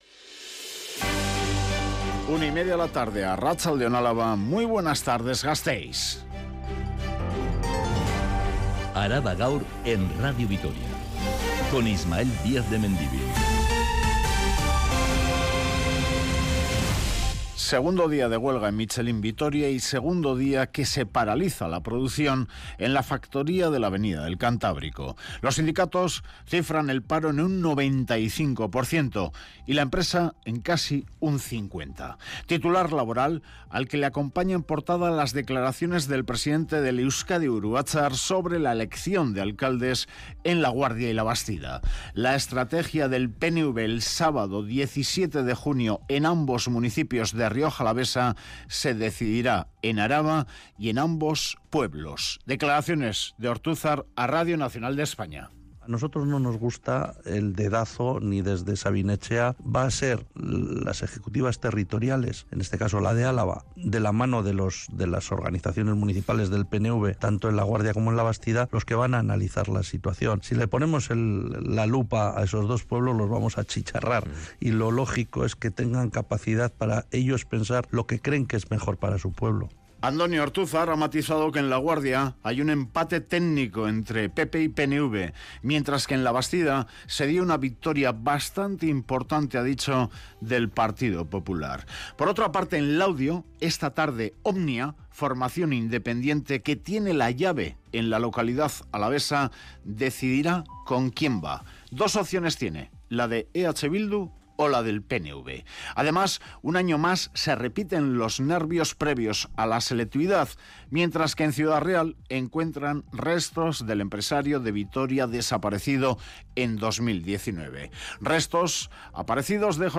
Radio Vitoria ARABA_GAUR_13H Araba Gaur (Mediodía) (07/06/2023) Publicado: 07/06/2023 14:34 (UTC+2) Última actualización: 07/06/2023 14:34 (UTC+2) Toda la información de Álava y del mundo. Este informativo que dedica especial atención a los temas más candentes de la actualidad en el territorio de Álava, detalla todos los acontecimientos que han sido noticia a lo largo de la mañana.